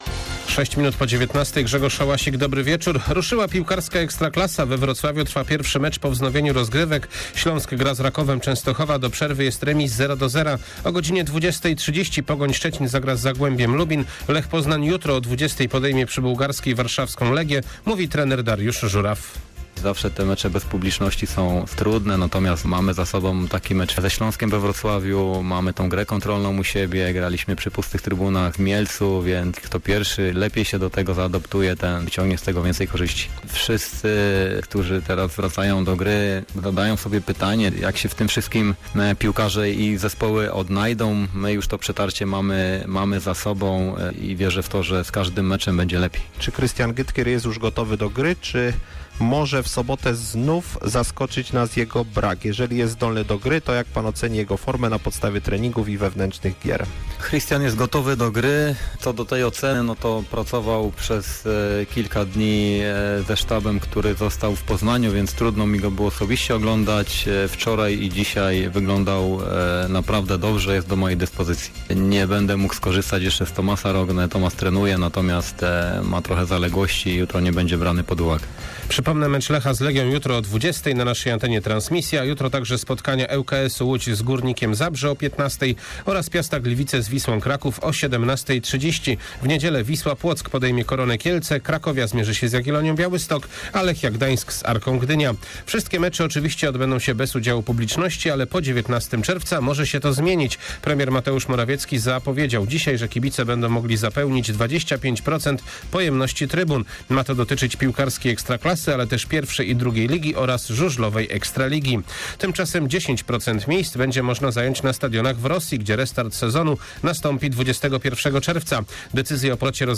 29.05. SERWIS SPORTOWY GODZ. 19:05